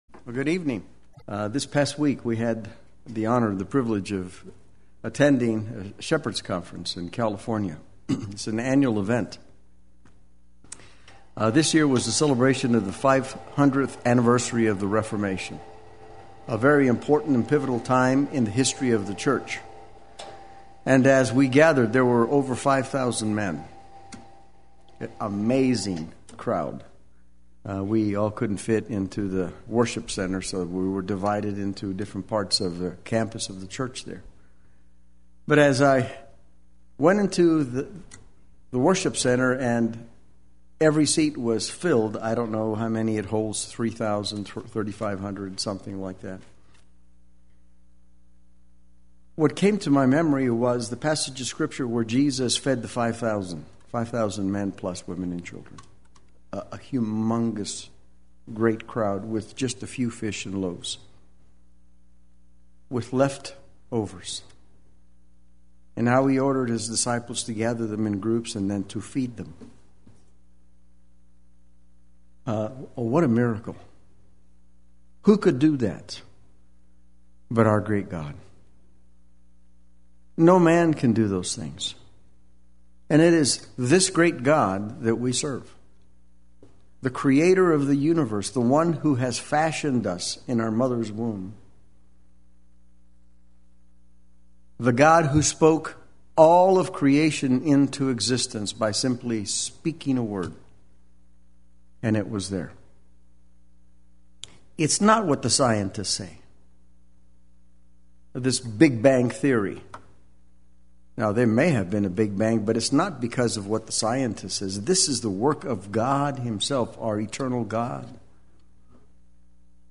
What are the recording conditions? Let Your Conduct Be Worthy of the Gospel of Christ Wednesday Worship